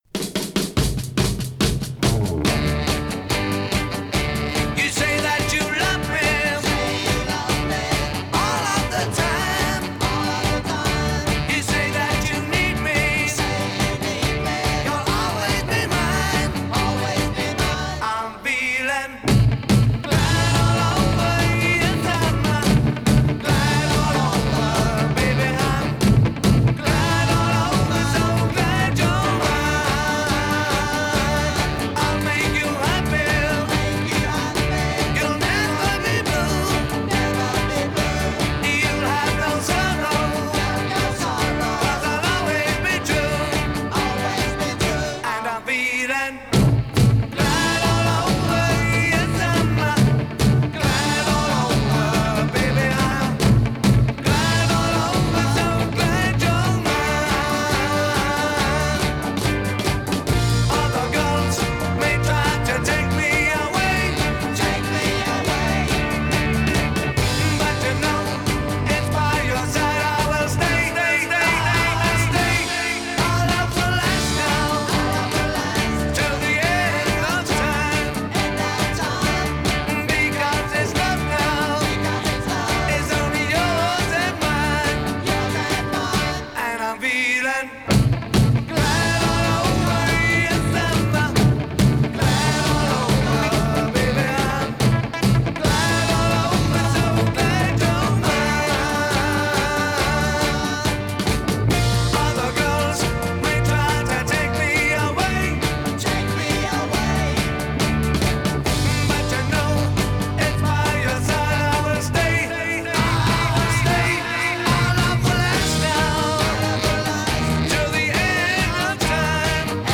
Another rare stereo version from the British “Best Of” LP.